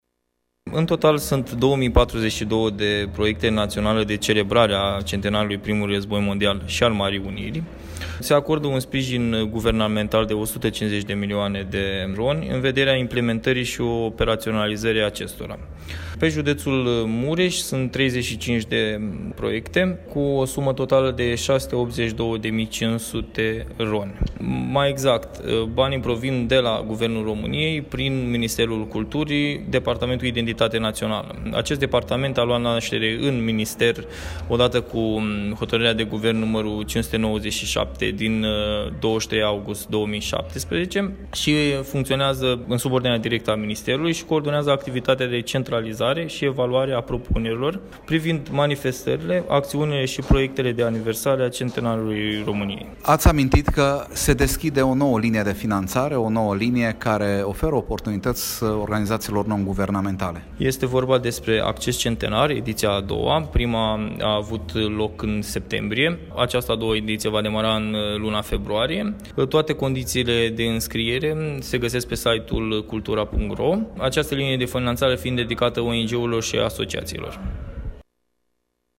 Într-un scurt interviu acordat Radio Tg.Mureș, secretarul de stat a declarat că până în acest moment au fost depuse la minister un număr de 2042 de proiecte de celebrare și comemorare a eroismului soldaților ce au luptat în Primul Război Mondial sau a celor care s-au implicat și au participat la Marea Unire, iar un număr de 35 din aceste proiecte au fost depuse de organizații și instituții din județul Mureș: